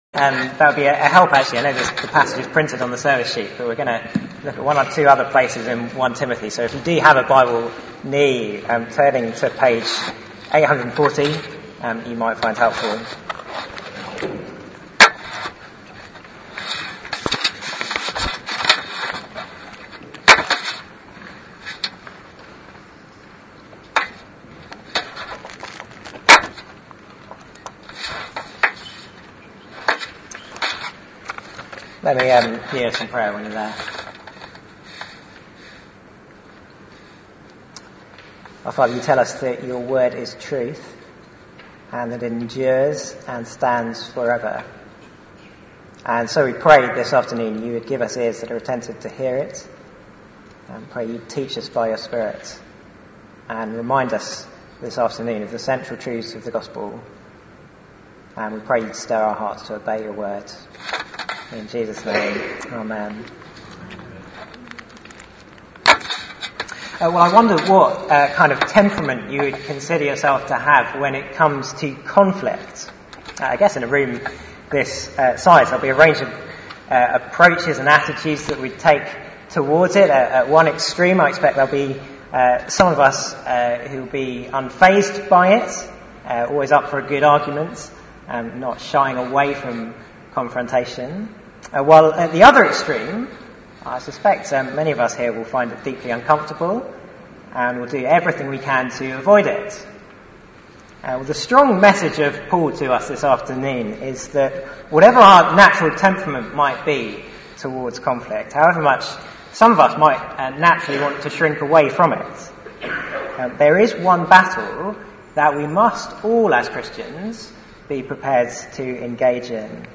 Theme: Keeping to the Gospel Sermon